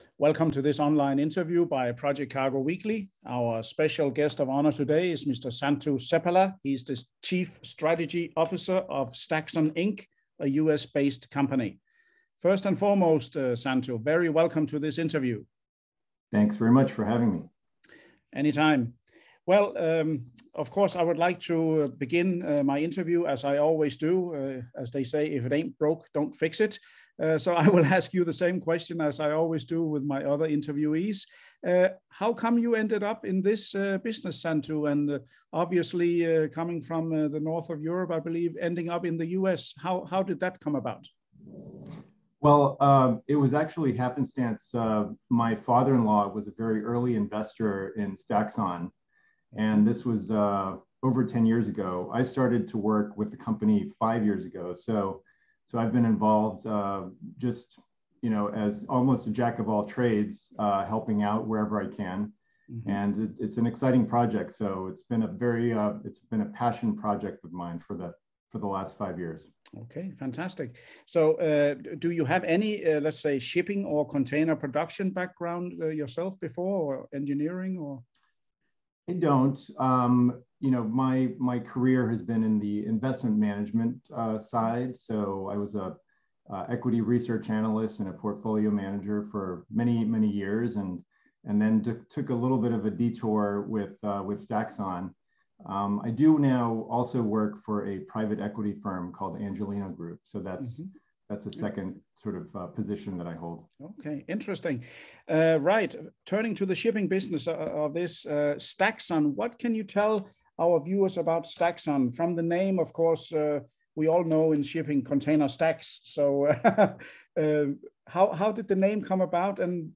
Video InterviewStaxxon, LLC